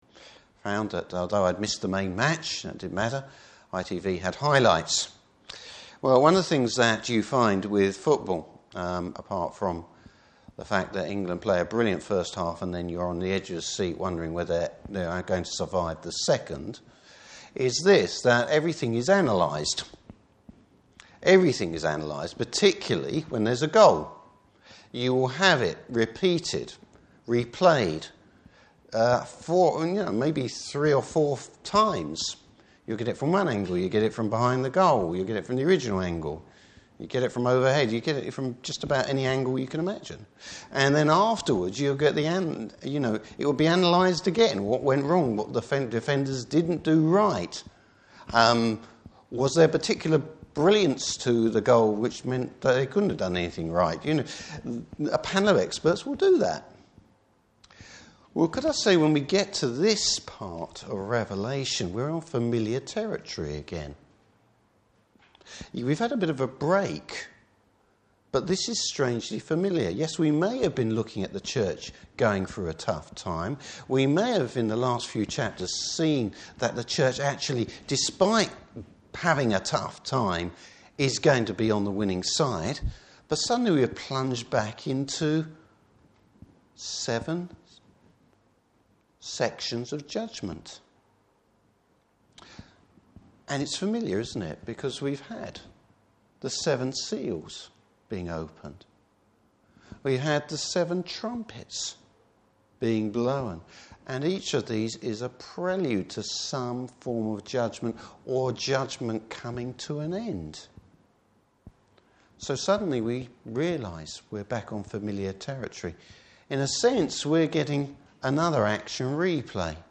Service Type: Evening Service Bible Text: Revelation 15.